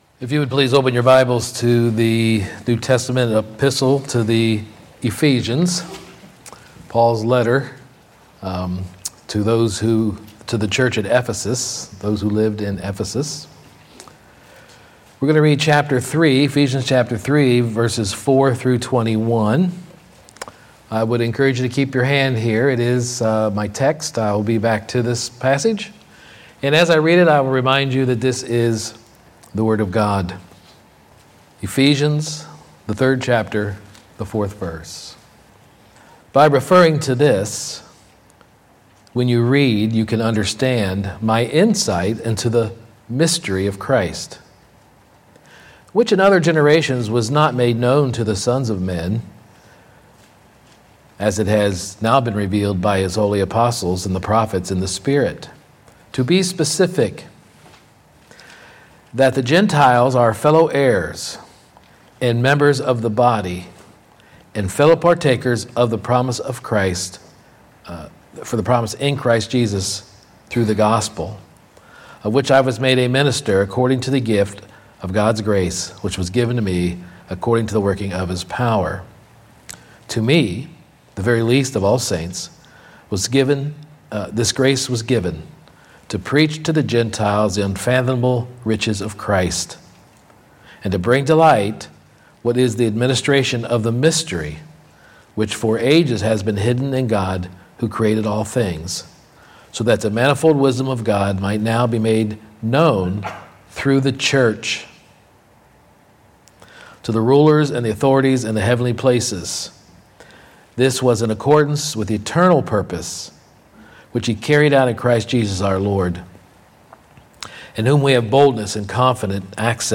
9-22-24-Sermon-The-Nature-of-the-Church.mp3